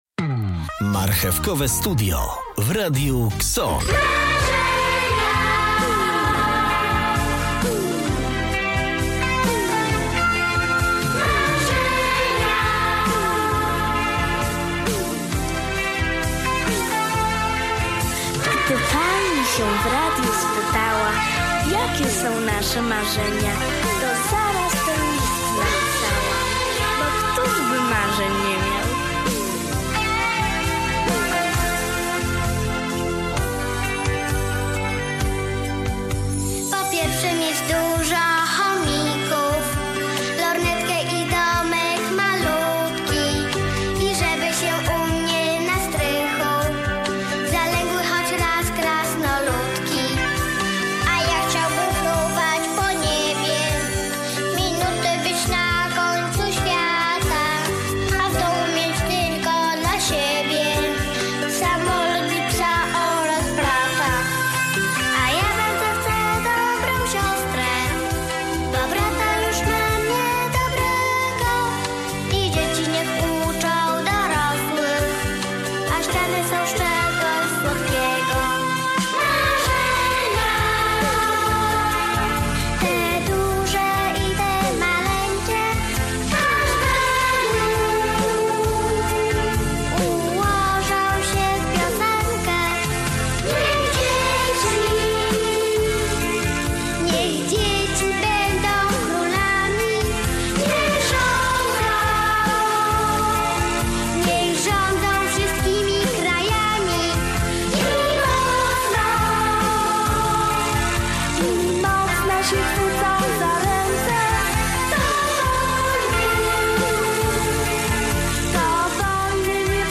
W tej audycji przy świetnej muzyce porozmawiamy o naszych marzeniach.